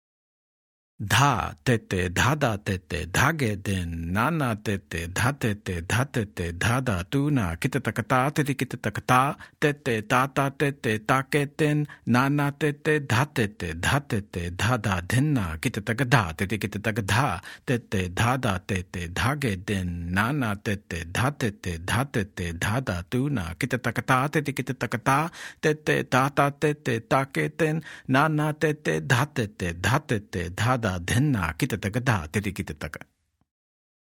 Demonstrations
1x Speed – Spoken